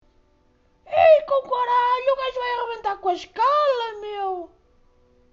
megakill_ultimate.mp3